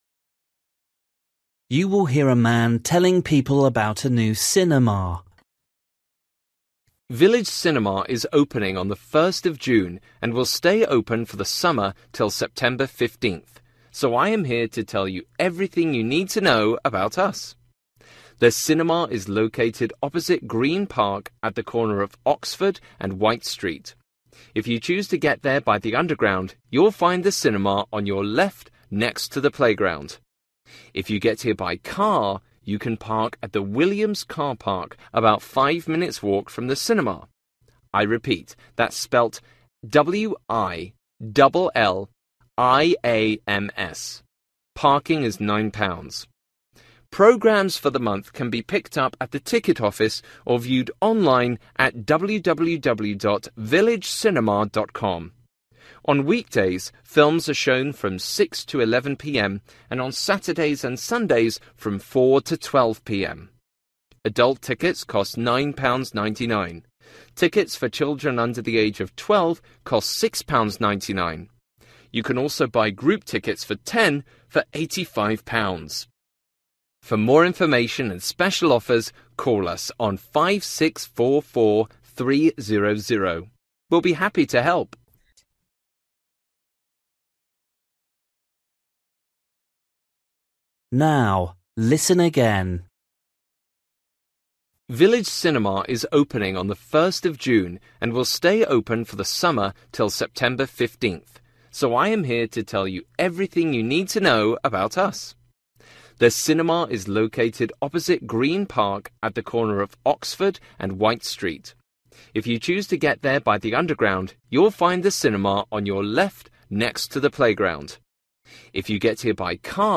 You will hear a man telling people about a new cinema.